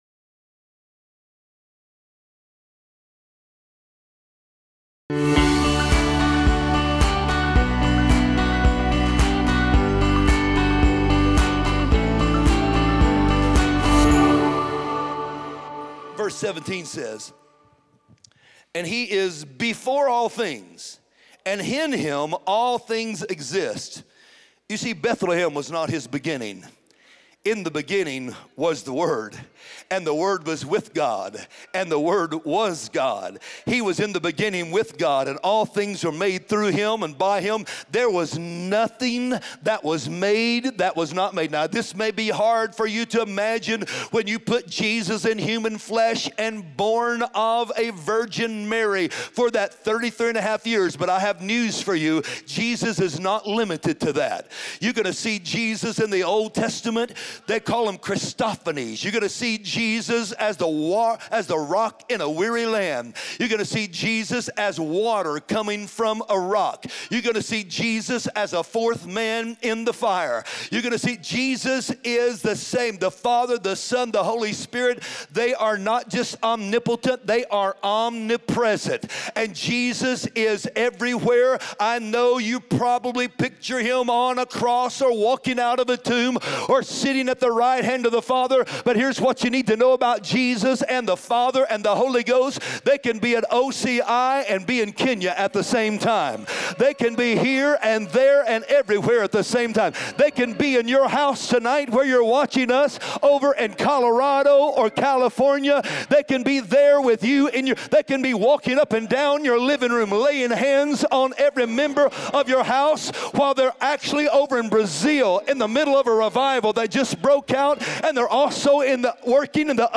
Join us this week for the sermon “Speak the Name of Jesus.”